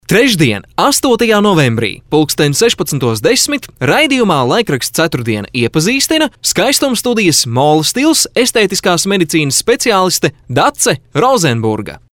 Professioneller lettischer Sprecher für TV / Rundfunk / Industrie.
Sprechprobe: Werbung (Muttersprache):
Professionell voice over artist from Latvia.